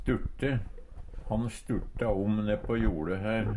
sturte - Numedalsmål (en-US)